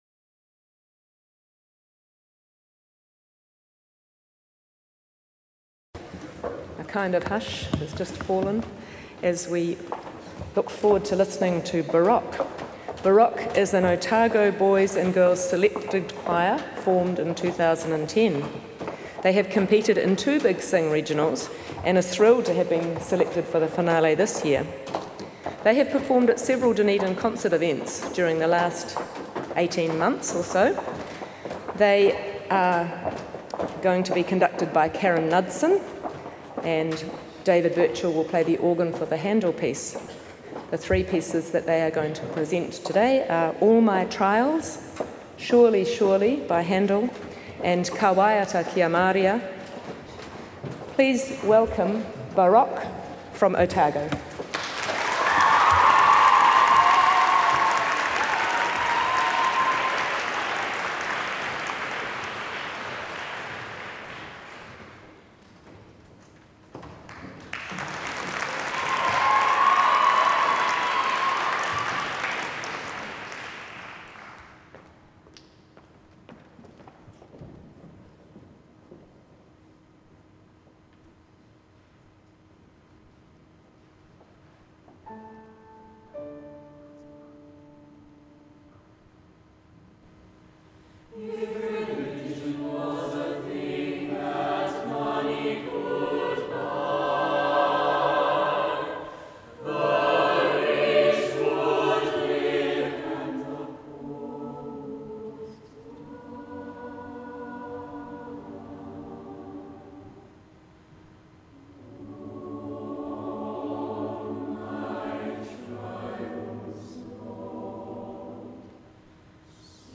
Recordings from The Big Sing National Final.